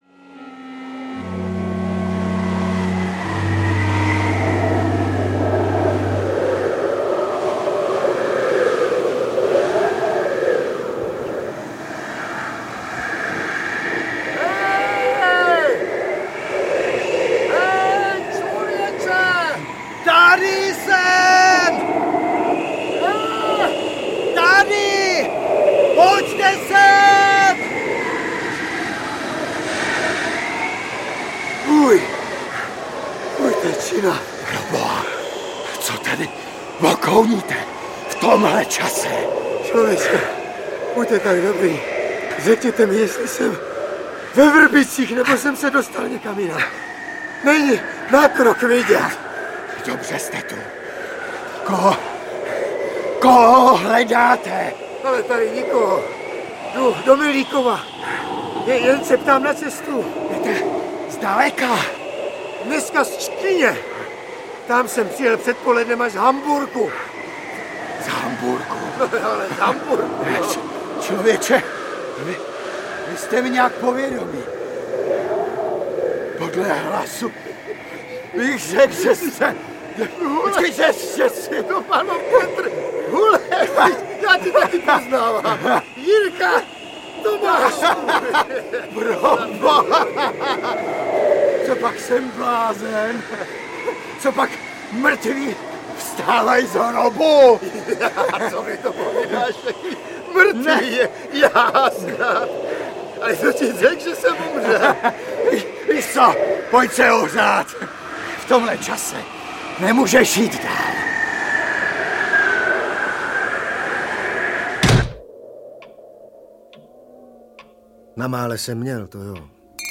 Mrtví se nevracejí a další povídky audiokniha
Ukázka z knihy